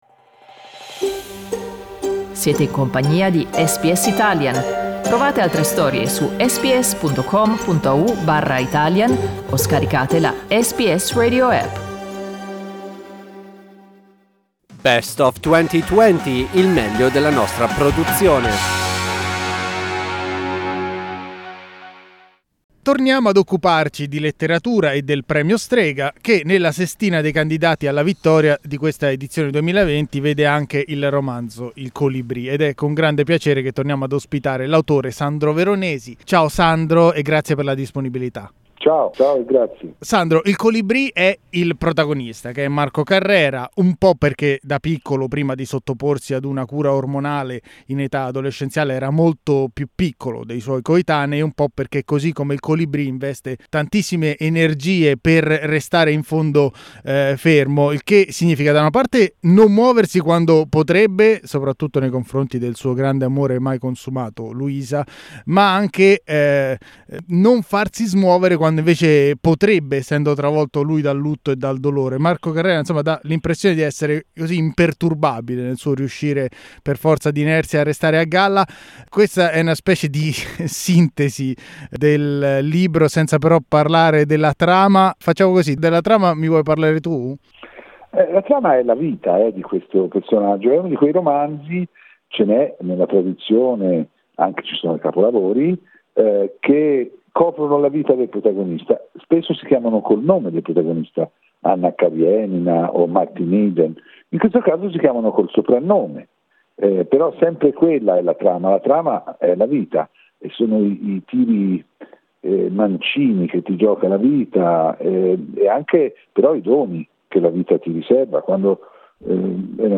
Vi riproponiamo un'intervista realizzata nel luglio del 2020 con Sandro Veronesi, che di lì a poco è stato dichiarato vincitore del Premio Strega con il suo libro Il colibrì.